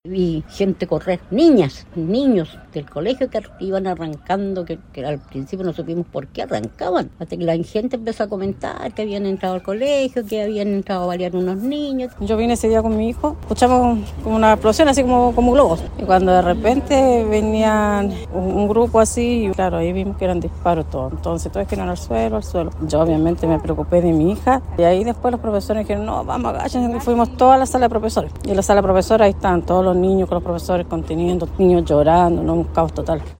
Apoderados relataron a La Radio cómo fue el momento de los disparos aquel jueves 29 de mayo, además de cómo se vivió la huida de los responsables.
mix-apoderados.mp3